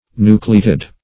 Nucleated \Nu"cle*a`ted\
nucleated.mp3